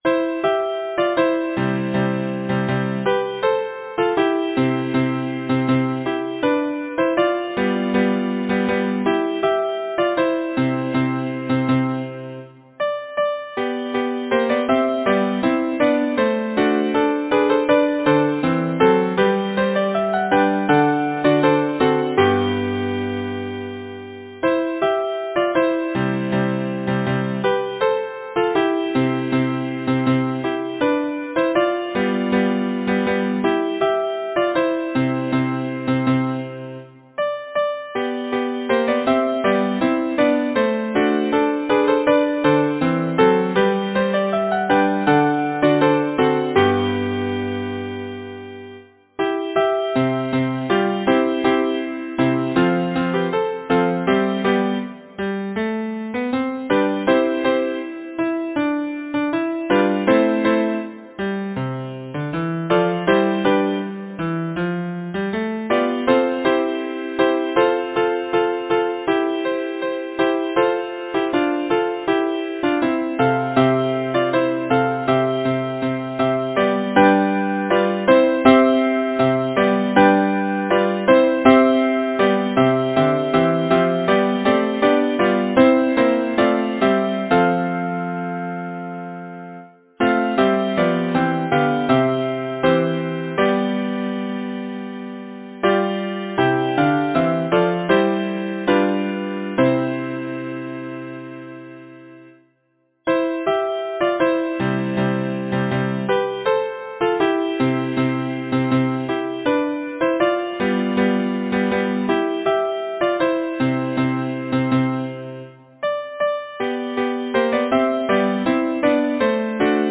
Title: Song of Spring Composer: John Harrison Tenney Lyricist: Anzentia Igene Perry Chapman Number of voices: 4vv Voicing: SATB Genre: Sacred, Partsong
Language: English Instruments: A cappella